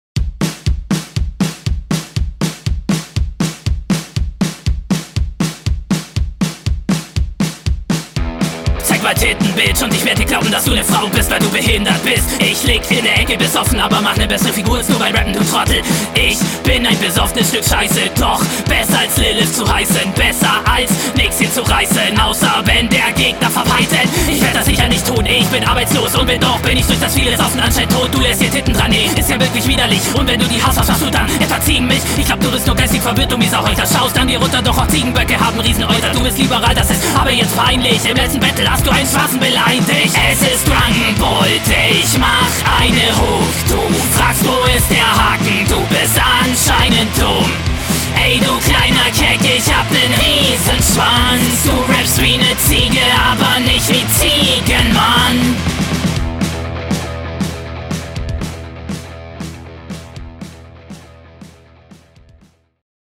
Der Flow passend gewählt und passt wirklich gut zum Beat.